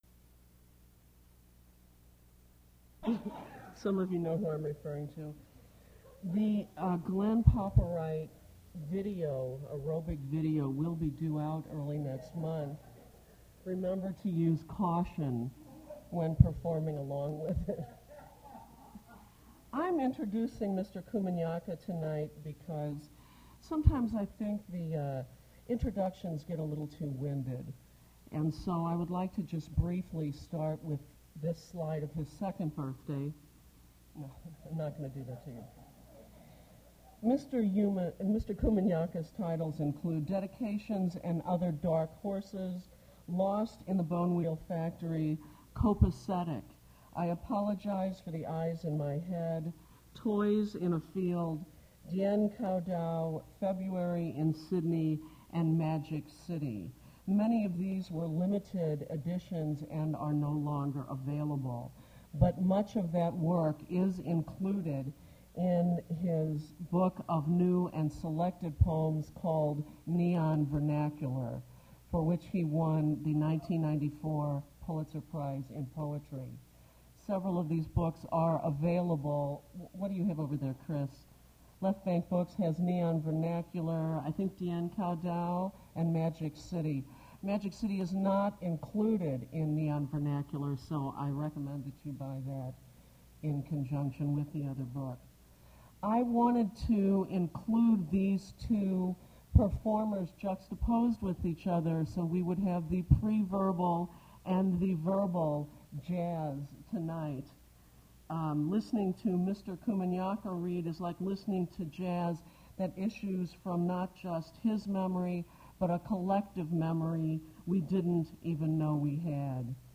Poetry reading featuring Yusef Komunyakaa
Attributes Attribute Name Values Description Yusef Komunyakaa poetry reading at Duff's Restaurant.
mp3 edited access file was created from unedited access file which was sourced from preservation WAV file that was generated from original audio cassette.
removed from technical difficulties from 15:00-15:15